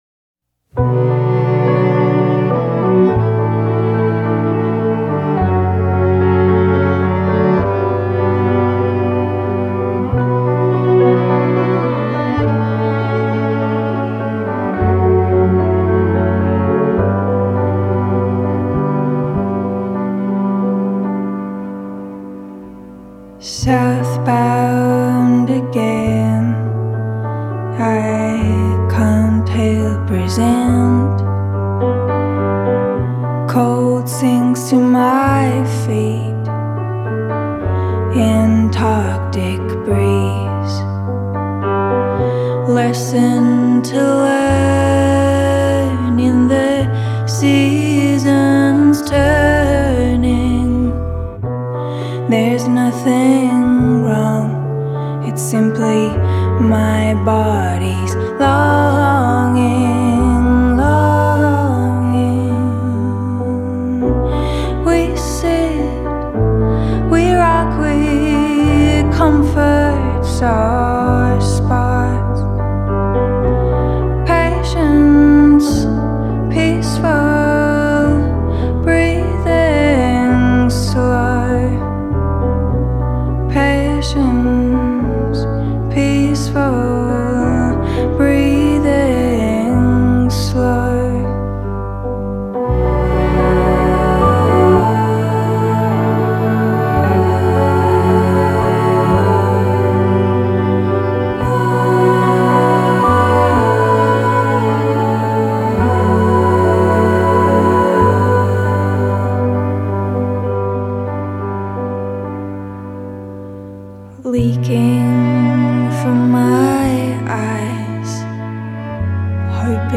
a delicate and meditative new single out Friday
Folk
Singer/Songwriter